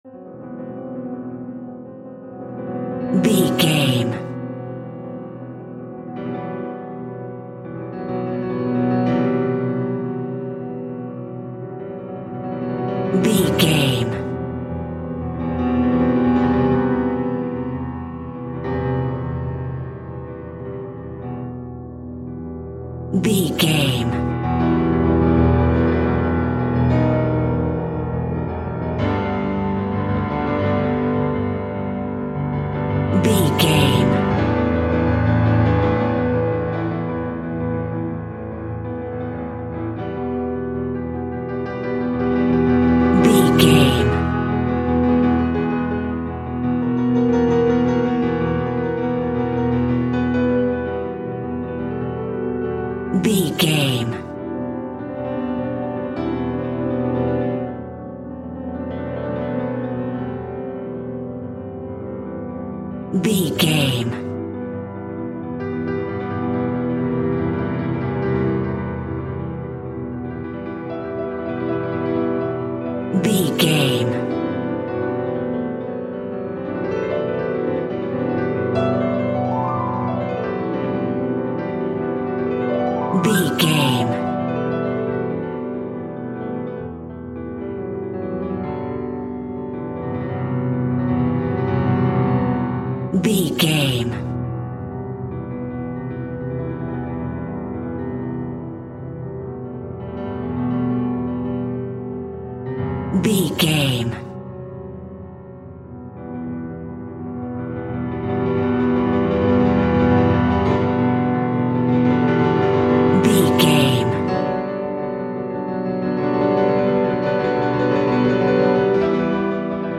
Aeolian/Minor
tension
ominous
dark
haunting
eerie
instrumentals